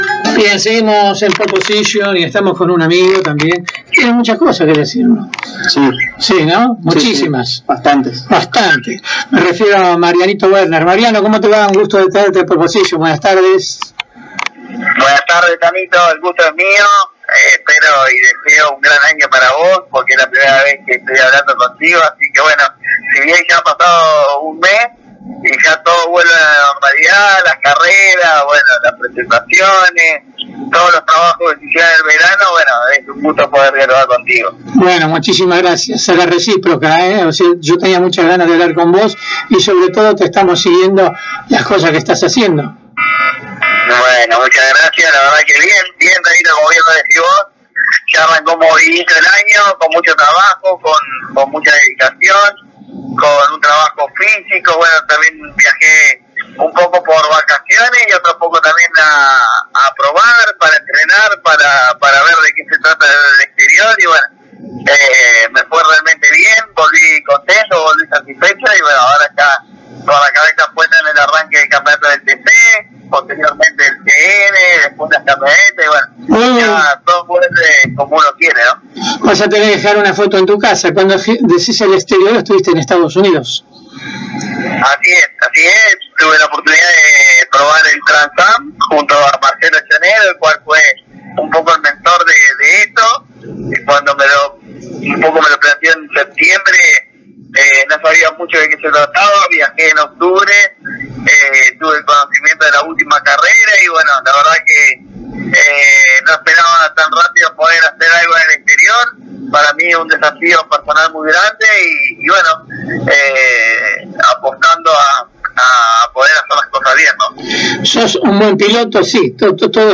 El entrerriano paso por los micrófonos de Pole Position y detalló como será su año deportivo, que incluye competencias en el exterior, donde estará compitiendo en la categoría Trans-Am, donde en el mes de enero tuvo las pruebas oficiales en Sebring. La actividad nacional seguirá de la misma manera, tanto en el Turismo Carretera como en TC Pickup, sumando al Turismo Nacional, como cuarta categoría para el entrerriano, en lo que será una temporada intensa.